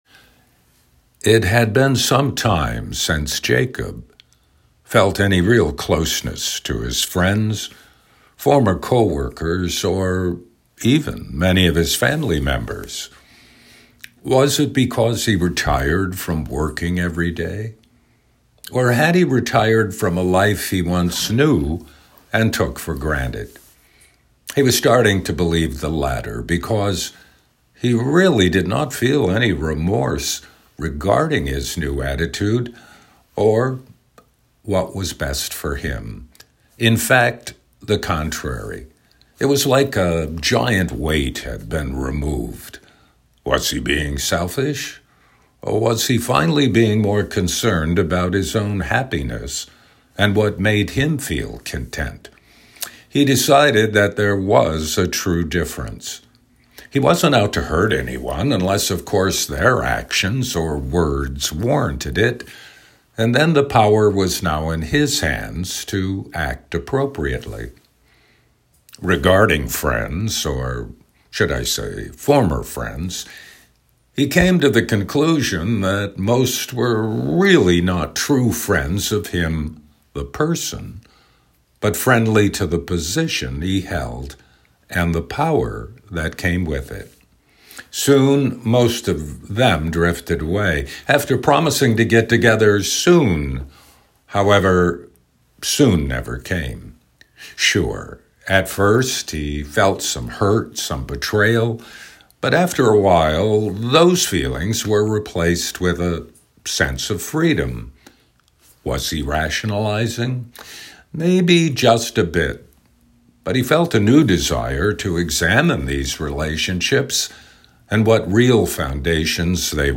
“Jacob,” Writing and Narration